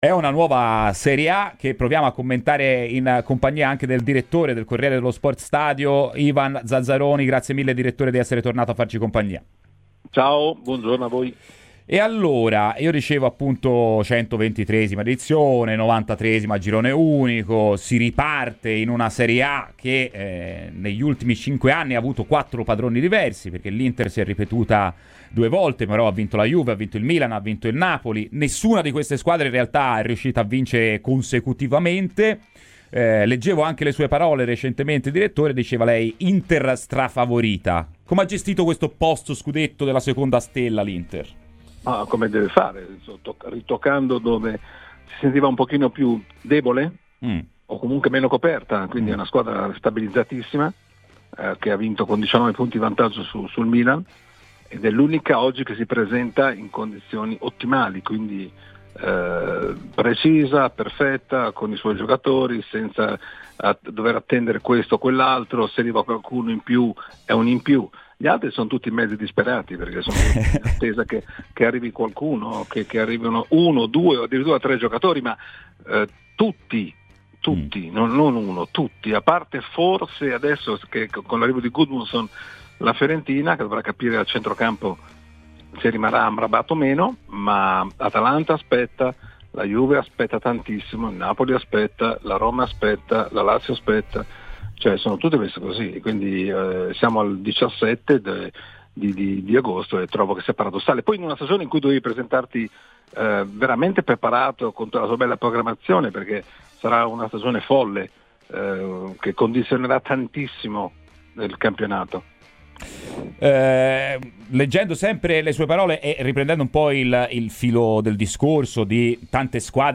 Ivan Zazzaroni, direttore del Corriere dello Sport-Stadio, è intervenuto ai microfoni di Radio FirenzeViola, durante "Firenze in Campo", per parlare del prossimo campionato: "L’Inter ha ritoccato dove si sentiva meno coperta ed è stabilizzatissima.